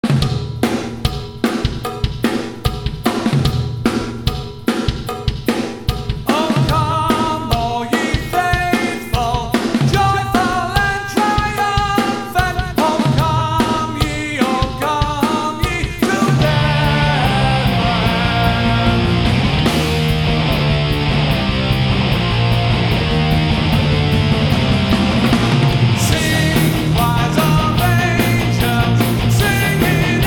heavy metal style.
Positive: The overall album is well mixed and composed.